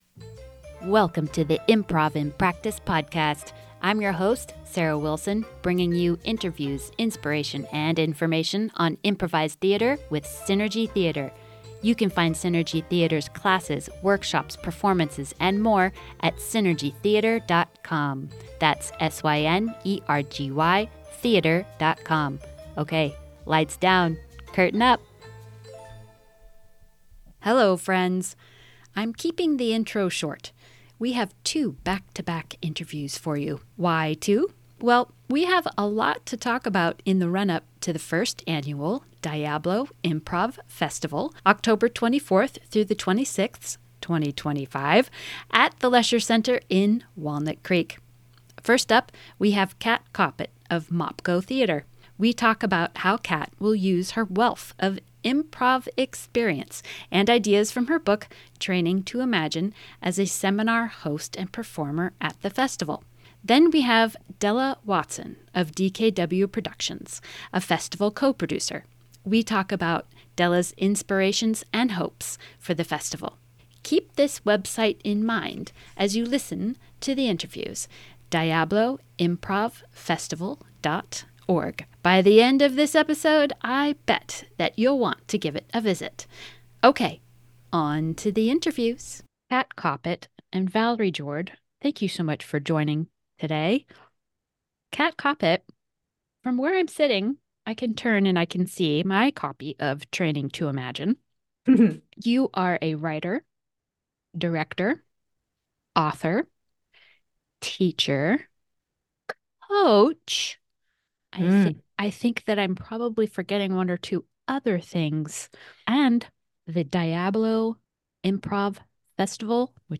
It's almost here: The first annual Diablo Improv Festival, October 24-26, 2025, at the Lesher Center in Walnut Creek, CA! We have a lot to talk about in the run up to the festival and so we have TWO back-to-back interviews for you.